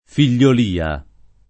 [ fil’l’ol & a ]